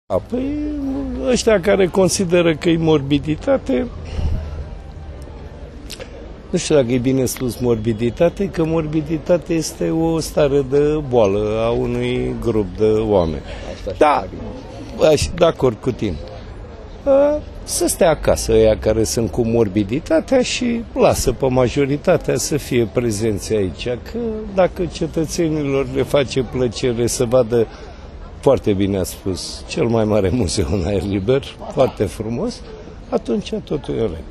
Primarul Capitalei Profesor Doctor Sorin Mircea Oprescu despre evenimentul din Bellu si despre Noaptea Muzeelor: